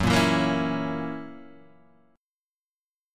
F# 7th